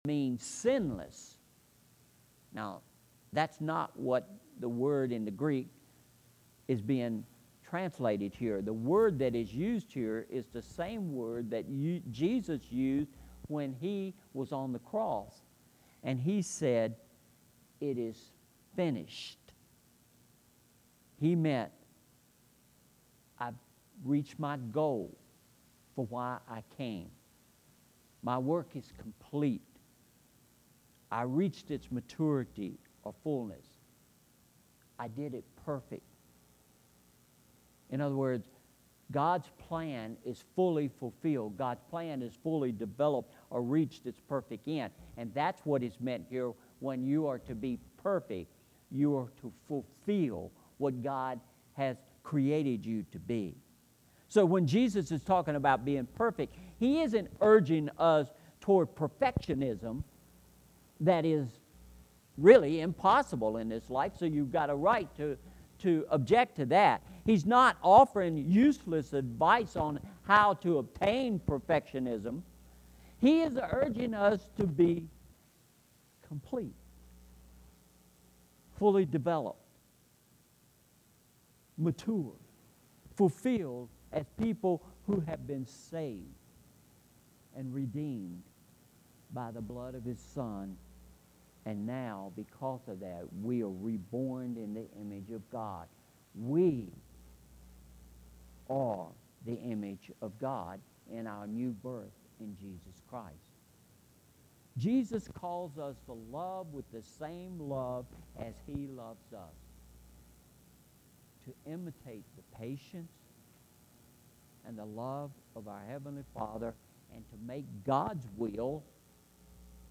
Sermon Title: “Loving Like God”